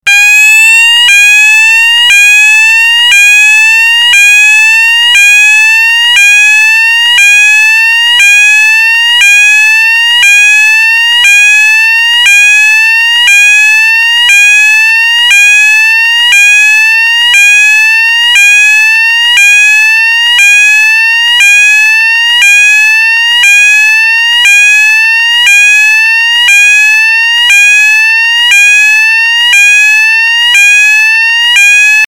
Waarschuwingssignaal Evacuatiesignaal
2019_evacuatiesignaal.mp3